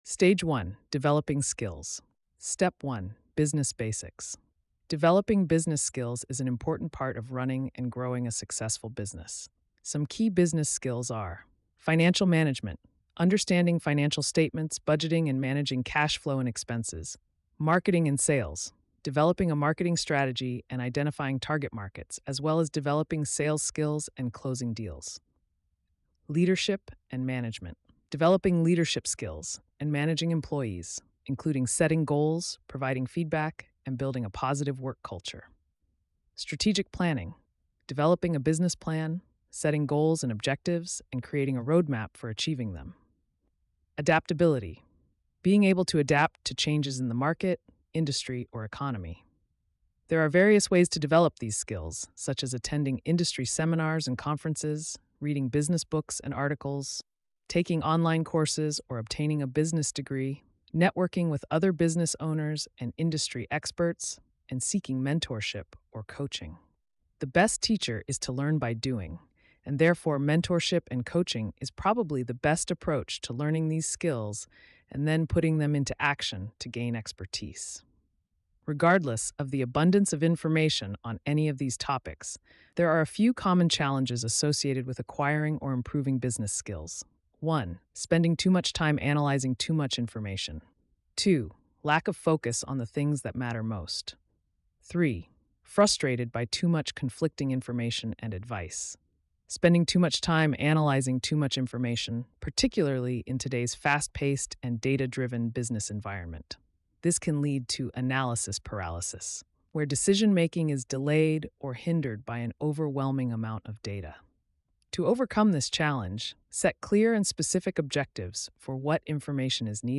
Read a Sample $7.99 Get the Audio Book You will be directed to Paypal to complete the purchase and then to BookFunnel for securely downloading your Audio Book.